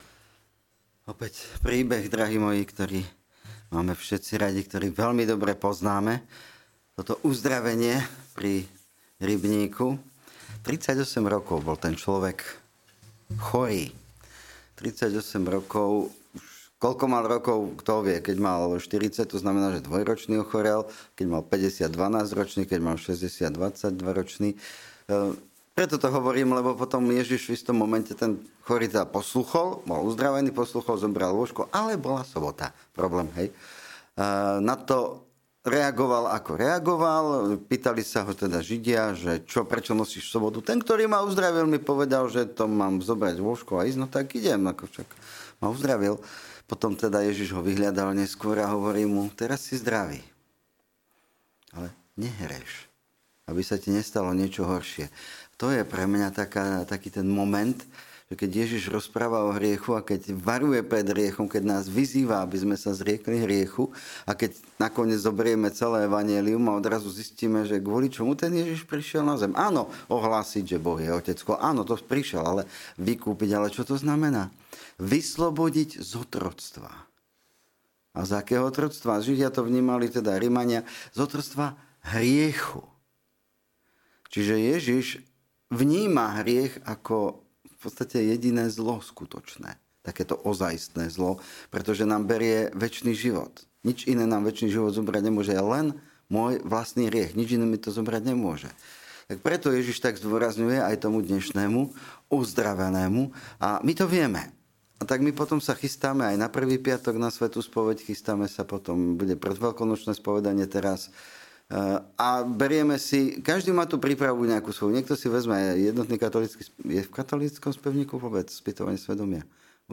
Podcasty Kázne DOBRE SA PRIPRAVME NA SPOVEĎ